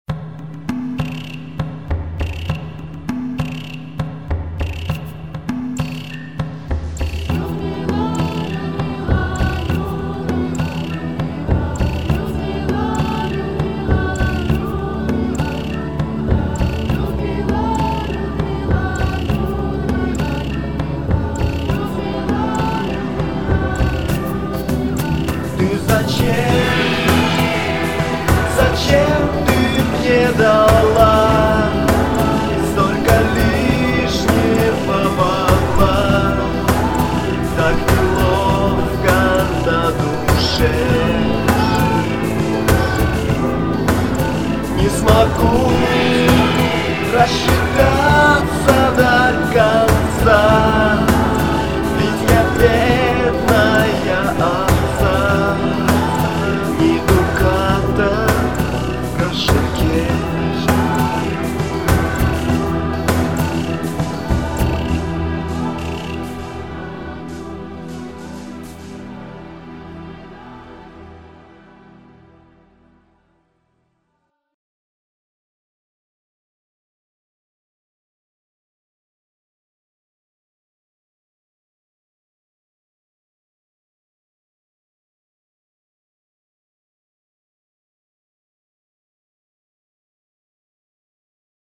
Хор гавно!
Композция оказалось очень сложной(.